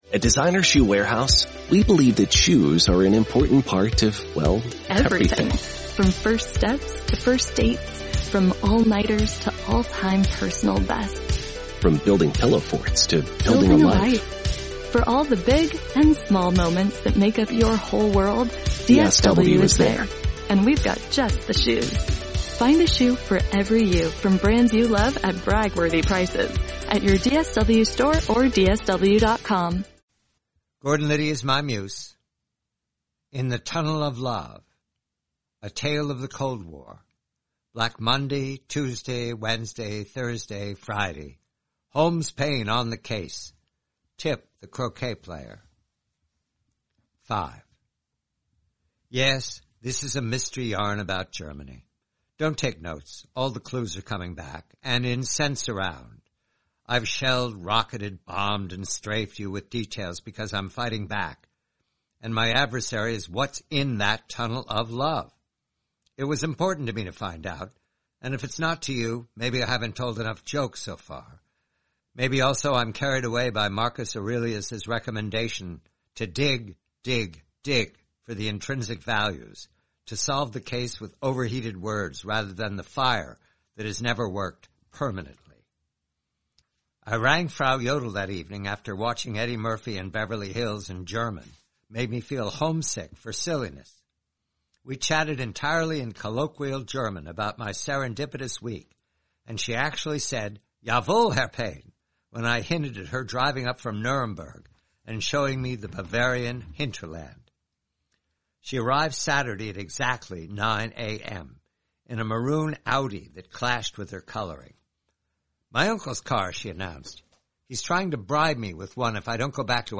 5/6: "In the Tunnel of Love," a story from the collection, "Gordon Liddy is My Muse," by John Calvin Batchelor. Read by John Batchelor.